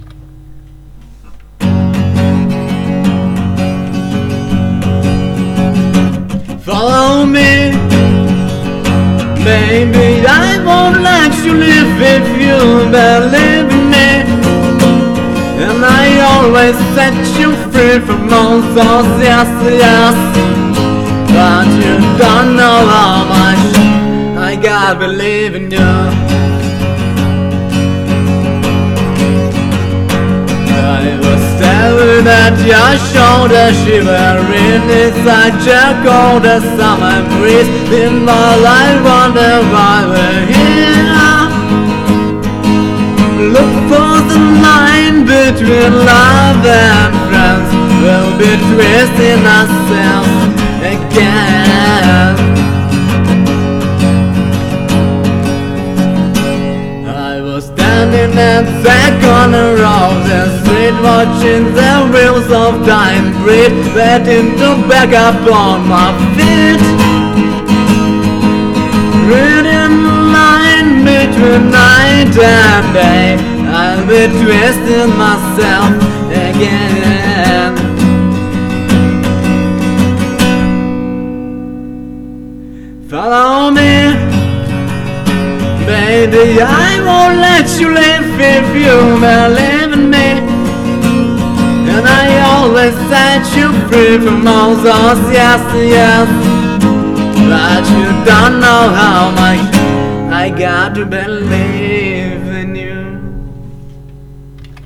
Окей, начал разучивать вокал, тренькаться, опора, грудак, прессак, все дела. Но такая фигня, что голос сам по себе высоковат, но диапазон "немного" ниже, чем изначально ожидал. От "Соль" большой октавы до "Ми" первой, притом едва-едва и впритык.